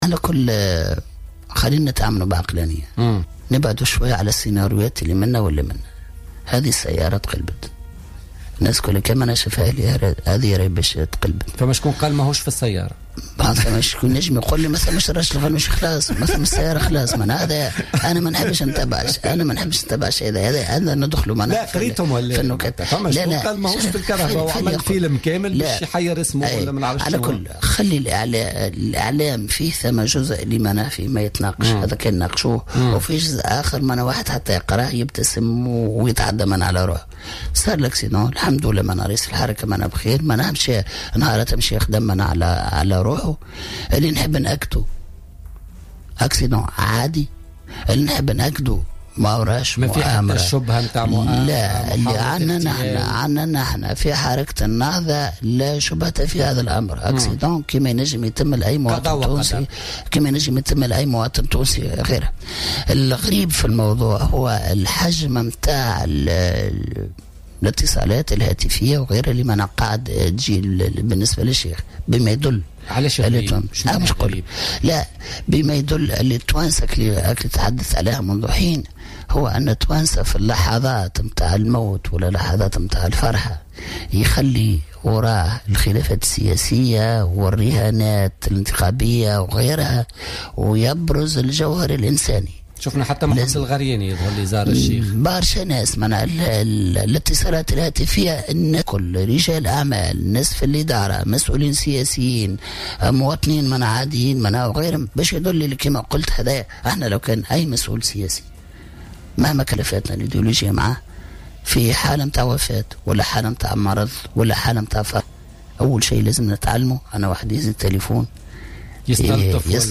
أكد عبد الحميد الجلاصي نائب رئيس حركة النهضة في تصريح للجوهرة أف أم في برنامج بوليتكا لليوم الأربعاء 27 أفريل 2016 أنه لا شبهة في الحادث الذي تعرض له رئيس الحركة راشد الغنوشي يوم الأحد الماضي.